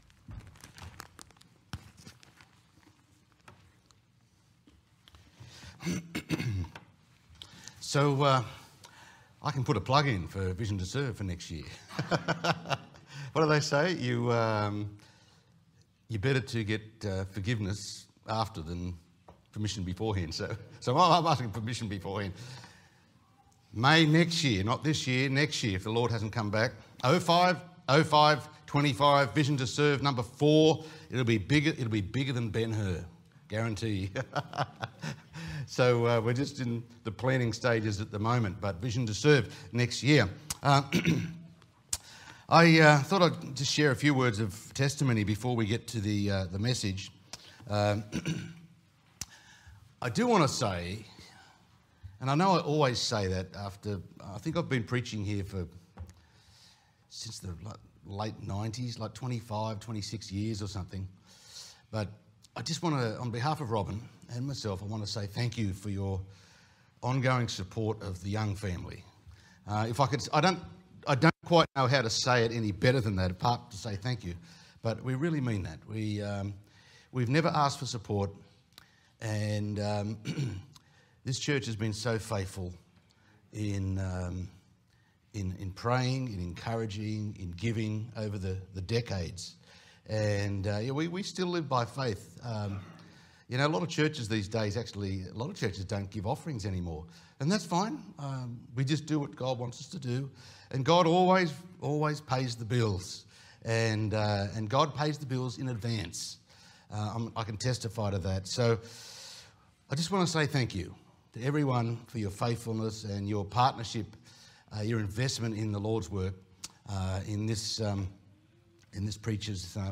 Sermons | Good Shepherd Baptist Church
Missions Conference 2024